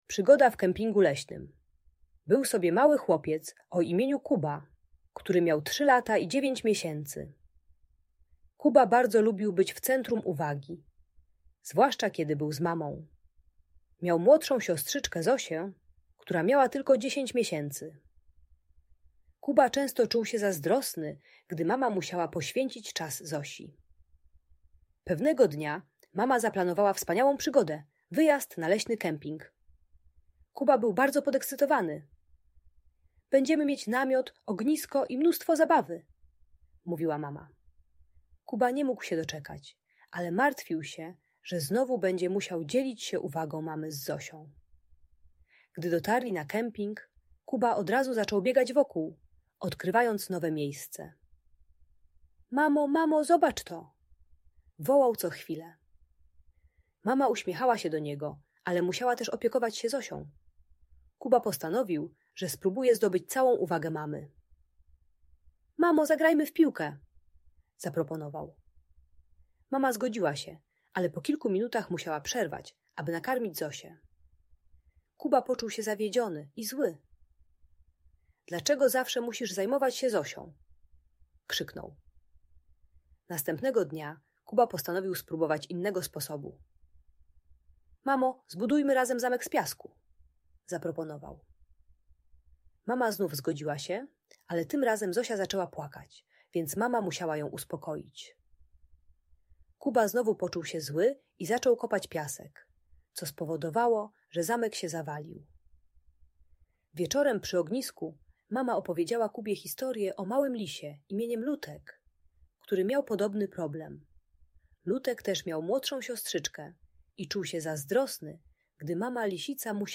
Przygoda w Kempingu Leśnym - Rodzeństwo | Audiobajka